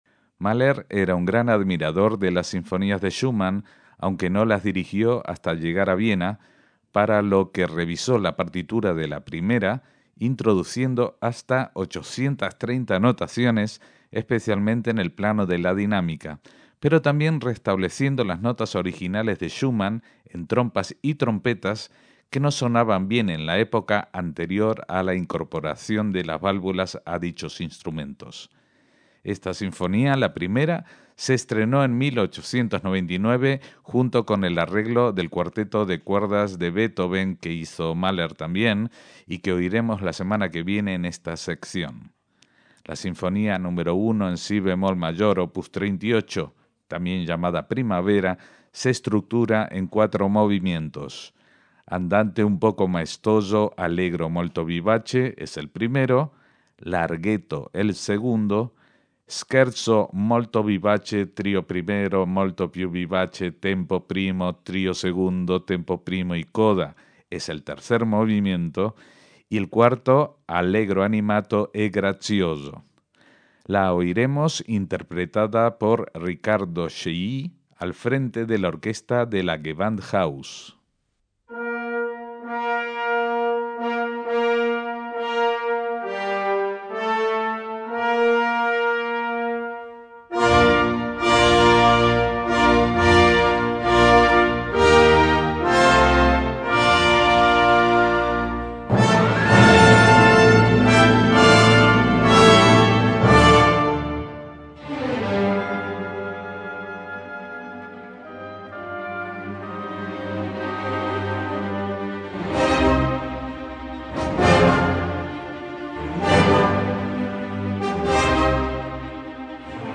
Arreglo de la Primera Sinfonía en si bemol mayor de Schumann, por Chailly y la Gewandhaus